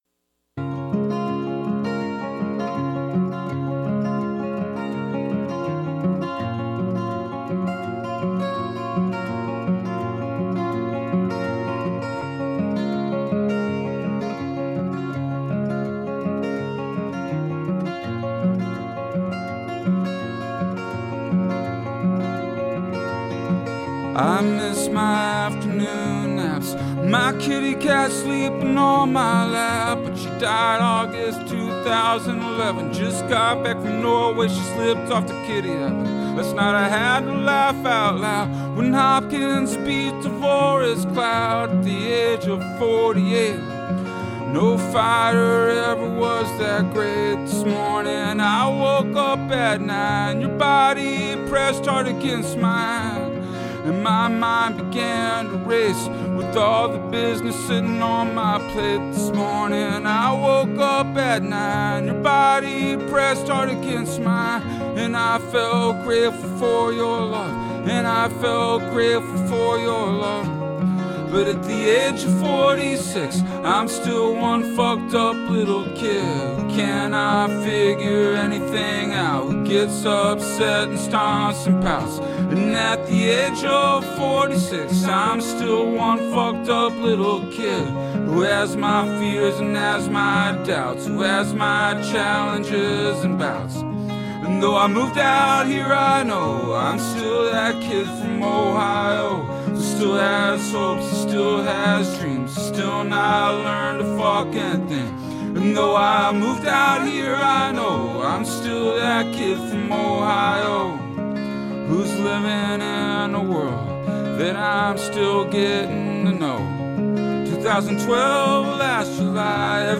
You’re invited to put on your boots and join us as we meander through the wild areas of our modern urban landscape, exploring contemporary and classic Americana, folk, country and elusive material that defies genre. New or artifact, urban or rural – City Folk is a curated field guide for the humble, a study in dirt and bone.